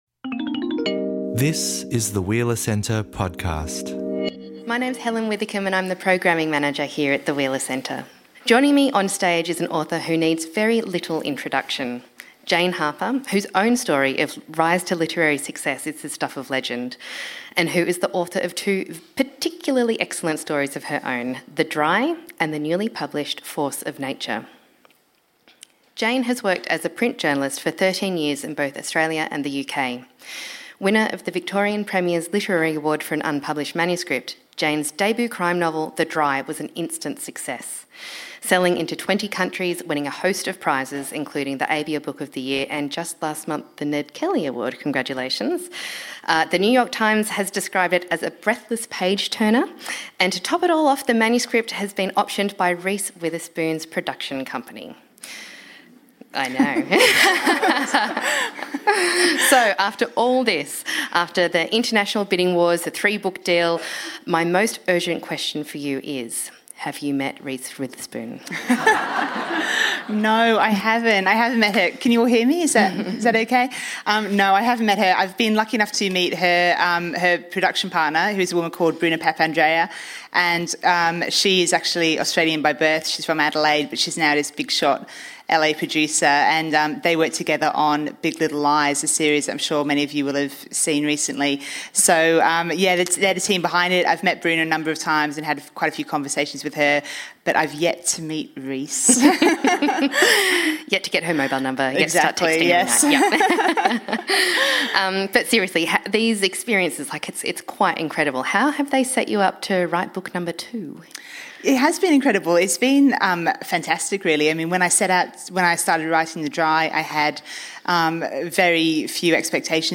a conversation about crime, conspiracy and runaway success